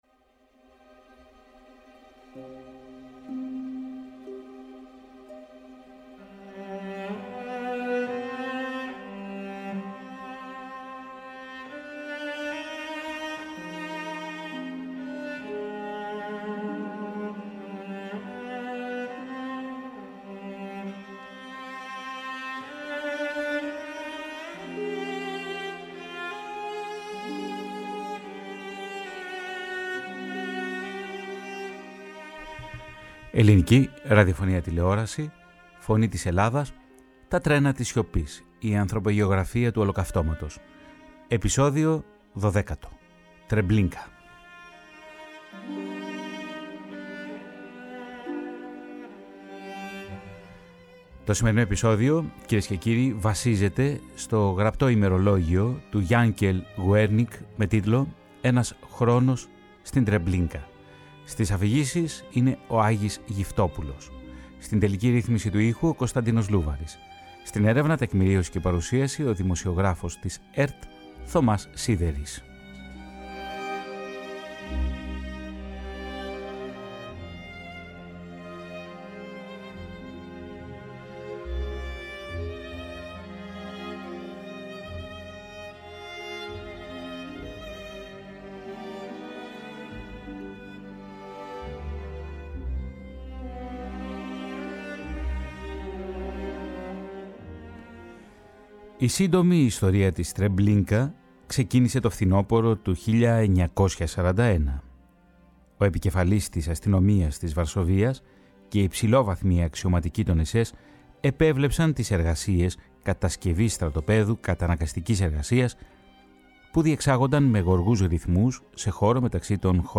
Το ραδιοφωνικό ντοκιμαντέρ στηρίζεται στο γραπτό ημερολόγιο του Γιάνκελ Γουέρνικ.